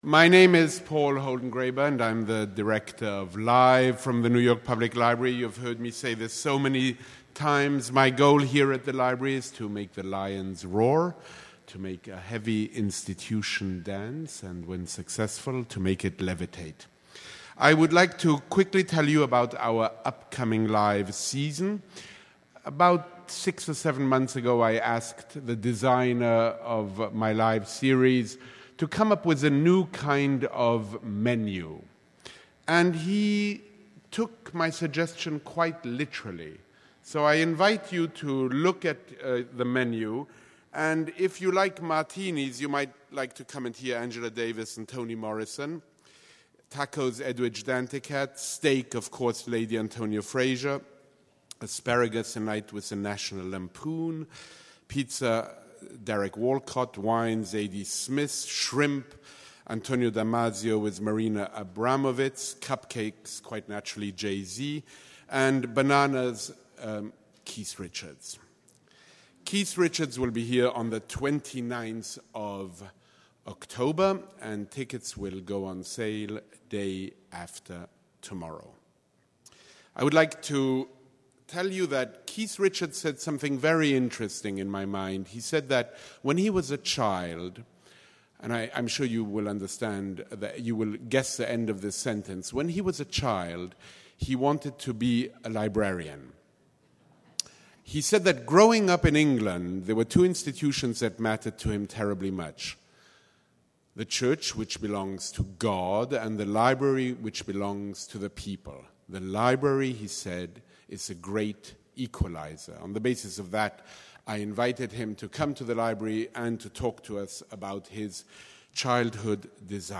STEVEN JOHNSON & KEVIN KELLY in conversation with Robert Krulwich